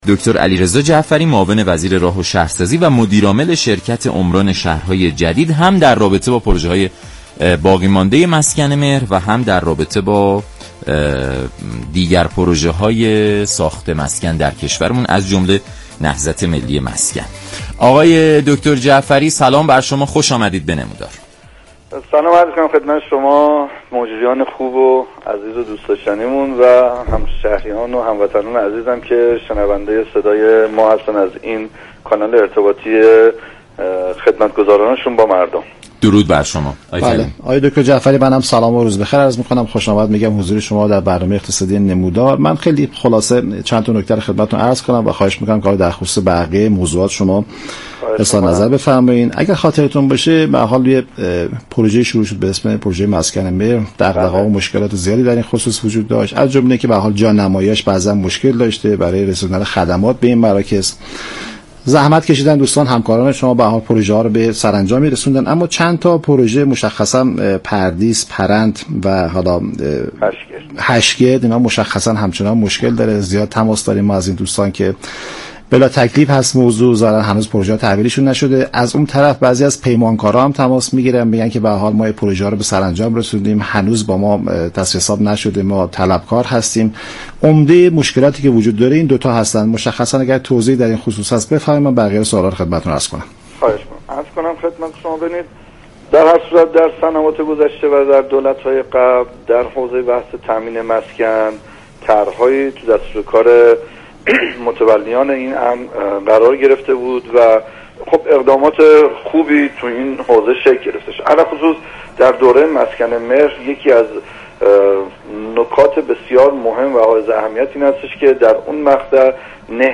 به گزارش شبكه رادیویی ایران، علیرضا جعفری معاون وزیر راه و شهرسازی در برنامه «نمودار» درباره نهضت ملی مسكن گفت: نهضت ملی مسكن كه ادامه راه طرح های دوره های پیشین است بیشتر به شهرسازی و توسعه زیرساخت ها توجه دارد.